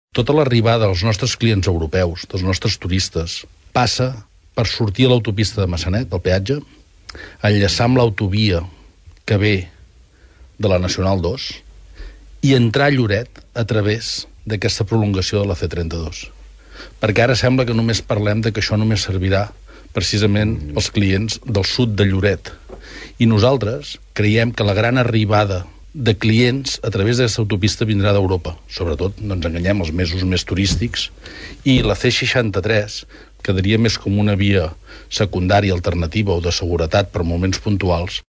a la tertúlia ciutadana de Nova Ràdio Lloret.